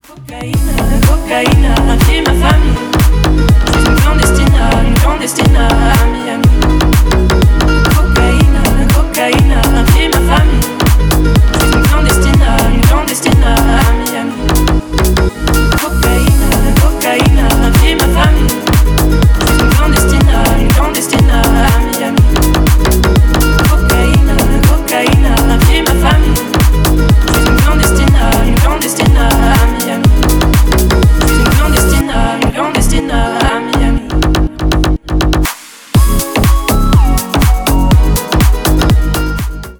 Ремикс
ритмичные # тихие